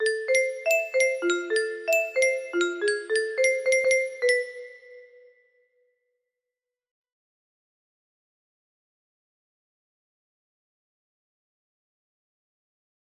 melody 1 music box melody